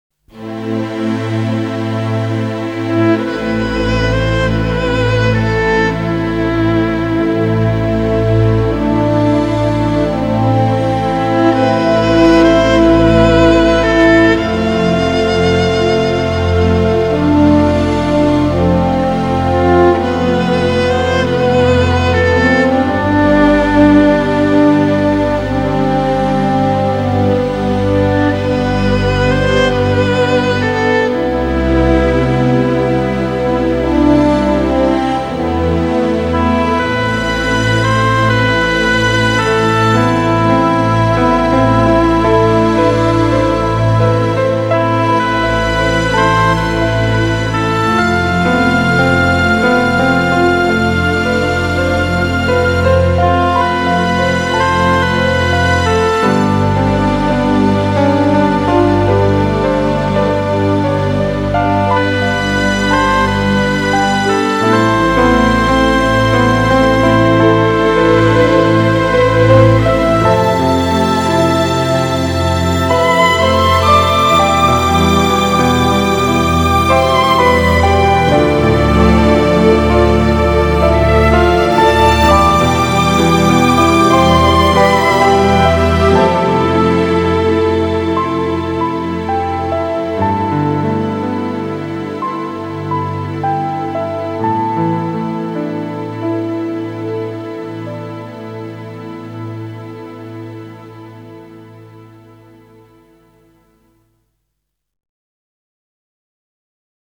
장르: Electronic, Jazz, Pop
스타일: Modern Classical, Easy Listening